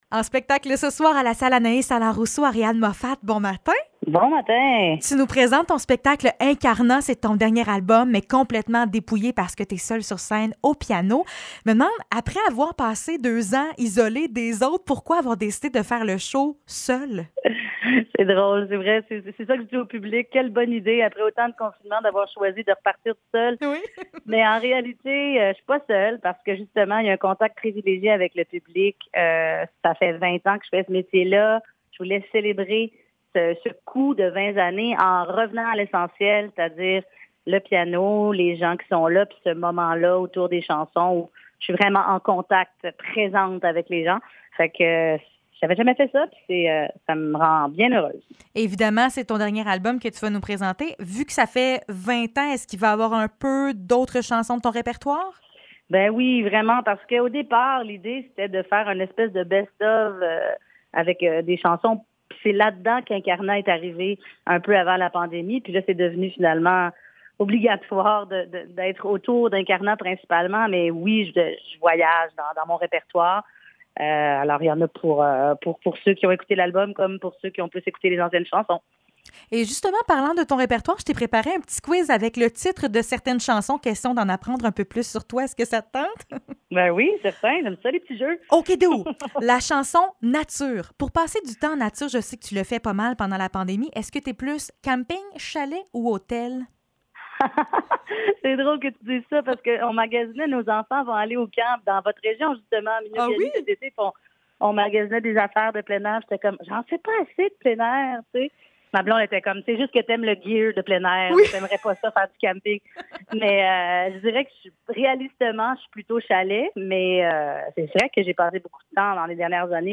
Entrevue avec Ariane Moffatt (7 juin 2022)